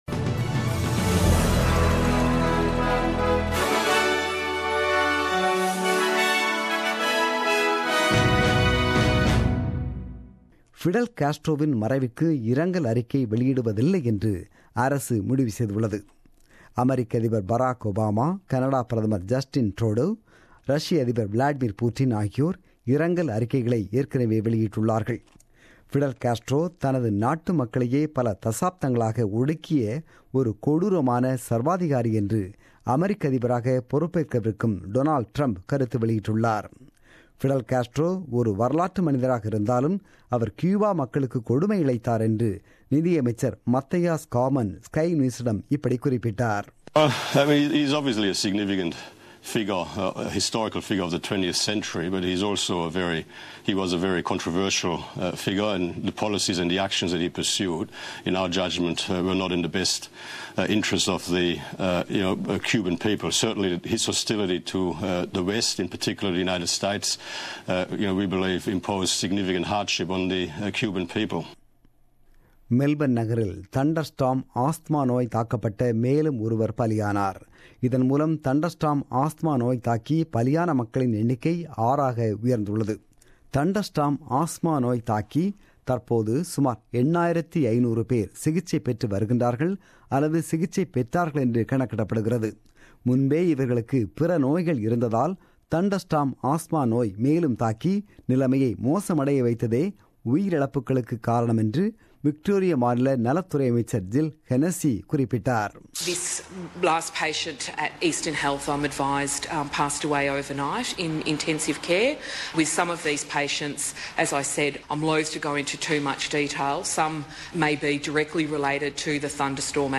The news bulletin broadcasted on 27 Nov 2016 at 8pm.